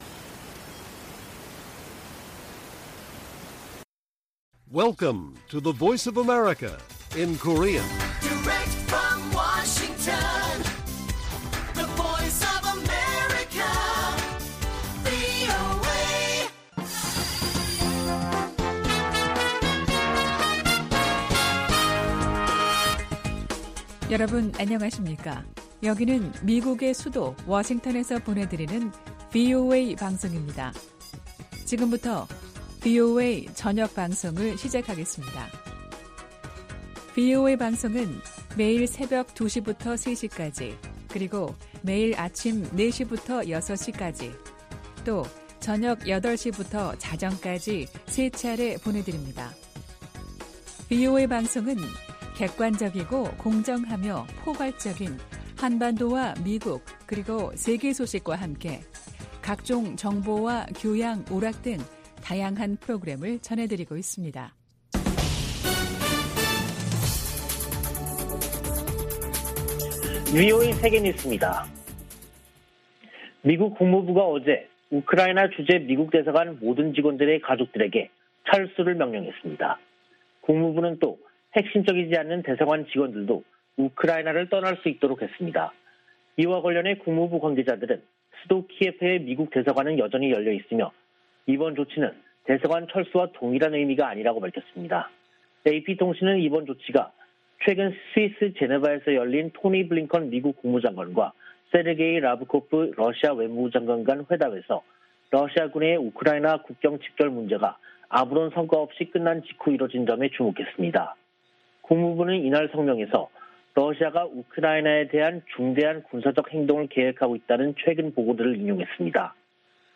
VOA 한국어 간판 뉴스 프로그램 '뉴스 투데이', 2022년 1월 24일 1부 방송입니다. 미-일 화상 정상 회담에서 북한의 잇따른 탄도미사일 시험 발사를 규탄하고 긴밀한 공조를 다짐했습니다. 미 국무부는 핵과 대륙간탄도미사일 실험 재개 의지로 해석된 북한의 최근 발표와 관련해 외교와 압박을 병행하겠다는 원칙을 확인했습니다. 미 국방부는 북한의 무기실험 재개 시사에 우려를 나타내면서도 외교적 관여 기조에는 변화가 없다고 밝혔습니다.